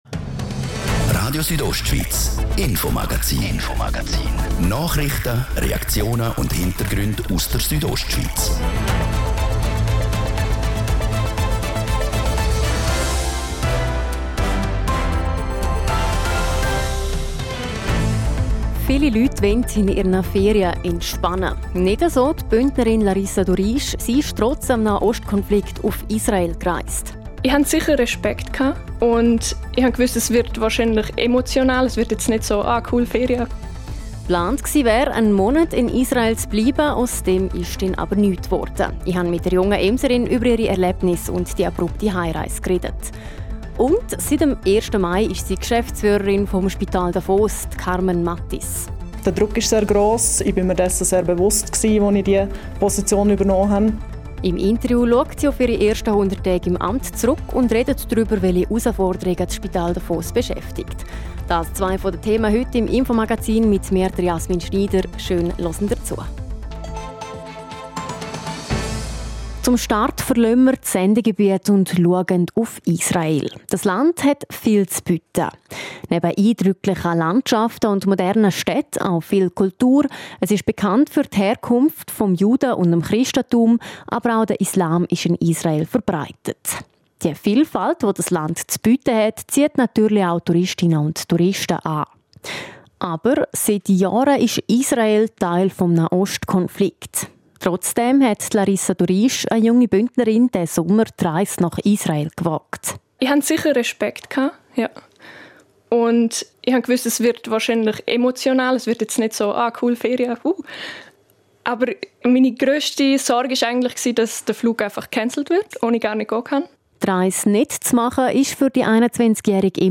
Eine Bündnerin erzählt, wie sie das Land aufgrund der Verschärfung des Nahostkonflikts verlassen musste.